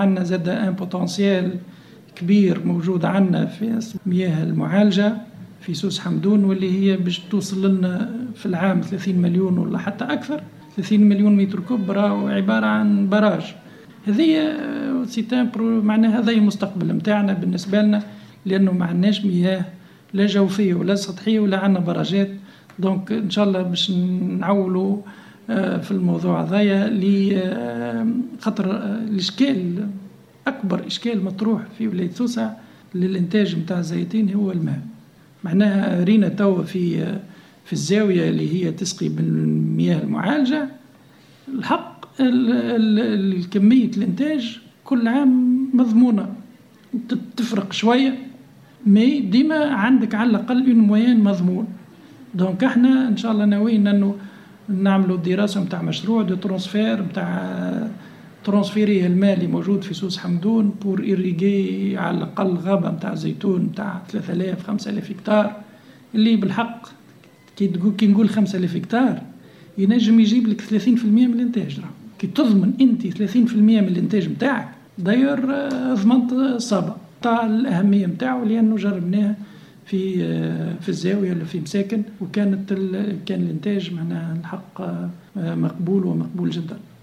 أوضح المندوب الجهوي للفلاحة بسوسة محمد العبيدي خلال حواره مع راديو “آر-ام افم” أن أبرز إشكال أمام تطوير قطاع الزياتين في ولاية سوسة هو ندرة مياه الري موضحا أن الحل لهذه المعضلة هو استعمال المياه المعالجة مستقبلا.